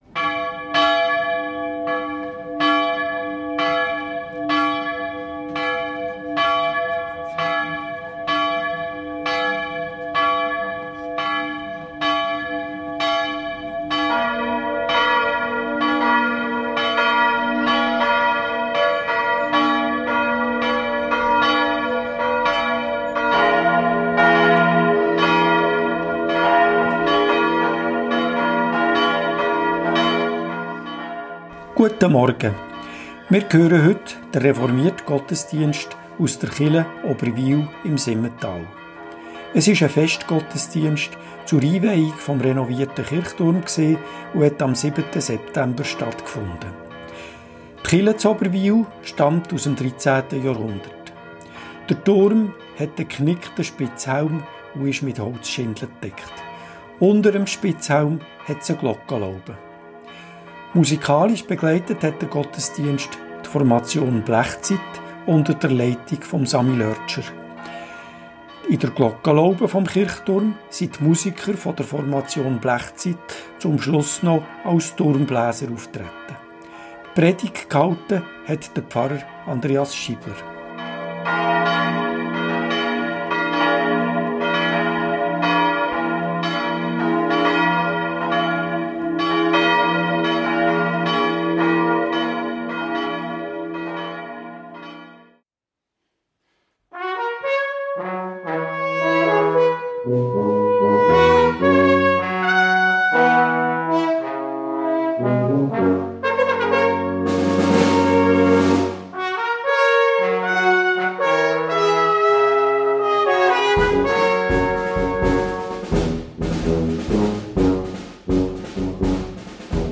Reformierte Kirche Oberwil im Simmental ~ Gottesdienst auf Radio BeO Podcast